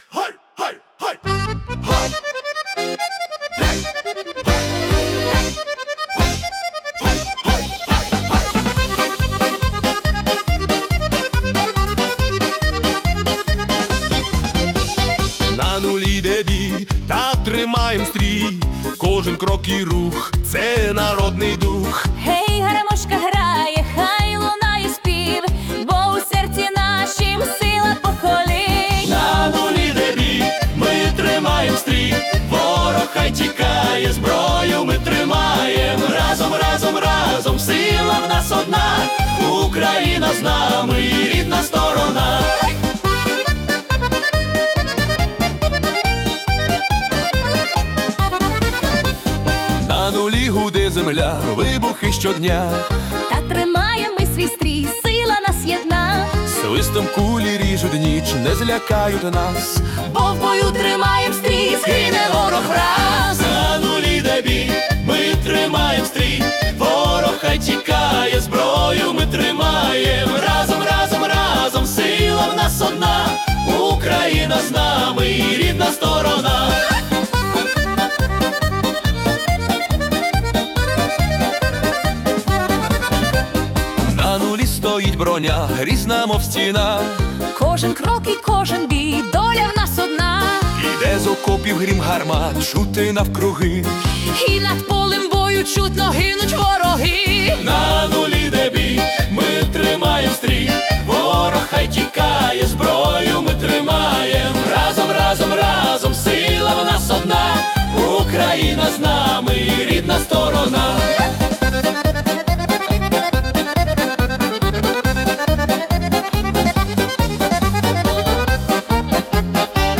🎵 Жанр: Ukrainian Military Polka
це неймовірно енергійна військова полька
(130-140 BPM)